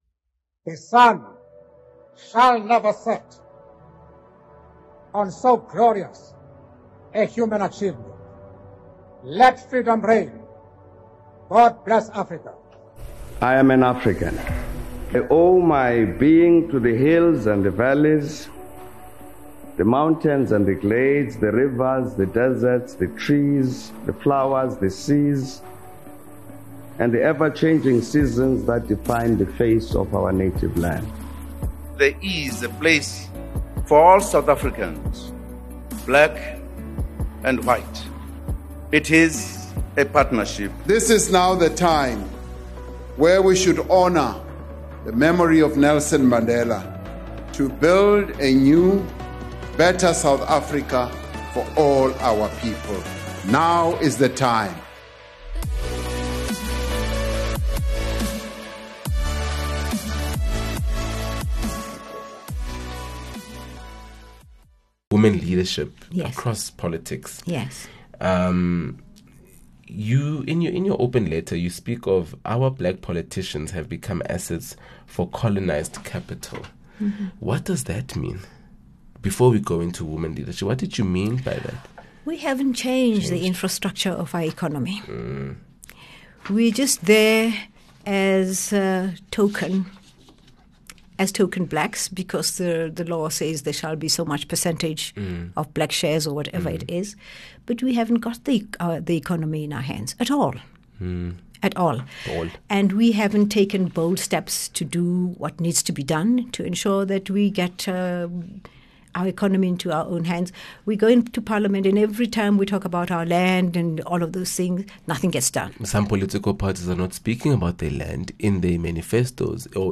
23 May In conversation with Lindiwe Sisulu